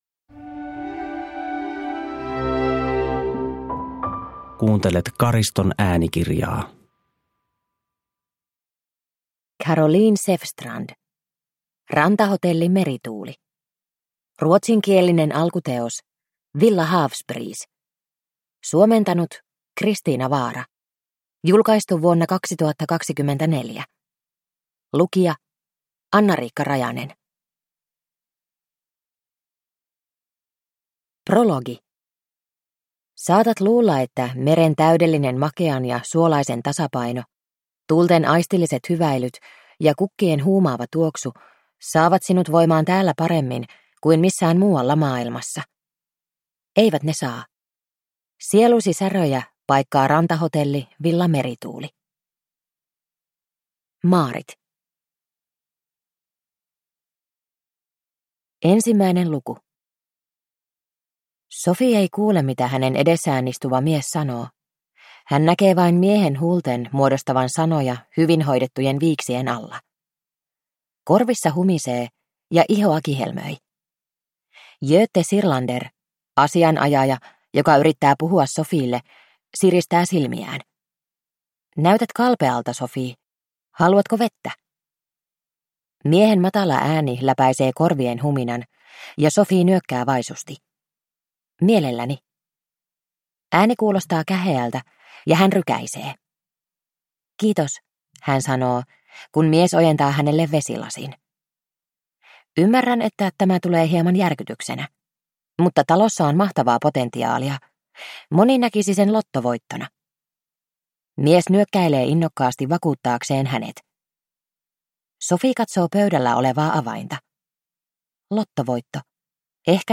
Rantahotelli Merituuli (ljudbok) av Caroline Säfstrand